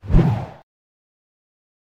Short Swoosh Sound Effect Free Download
Short Swoosh